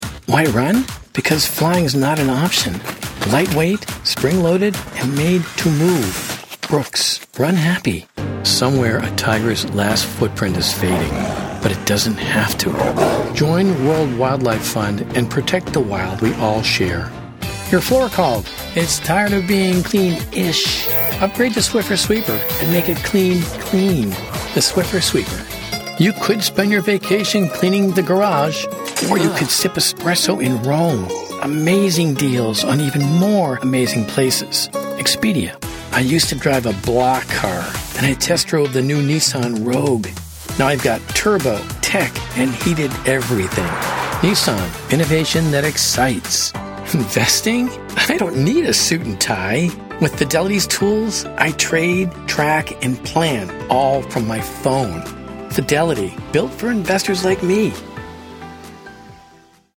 Adult (30-50) | Older Sound (50+)